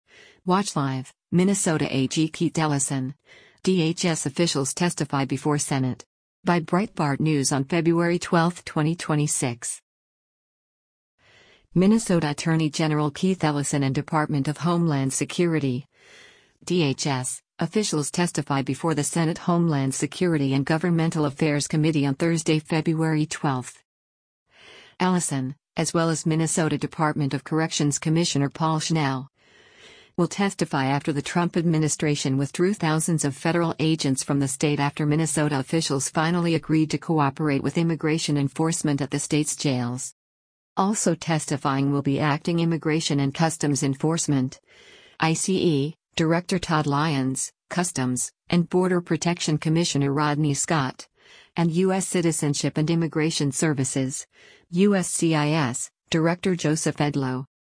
Minnesota Attorney General Keith Ellison and Department of Homeland Security (DHS) officials testify before the Senate Homeland Security and Governmental Affairs Committee on Thursday, February 12.
Also testifying will be Acting Immigration and Customs Enforcement (ICE) Director Todd Lyons, Customs and Border Protection Commissioner Rodney Scott, and U.S. Citizenship and Immigration Services (USCIS) Director Joseph Edlow.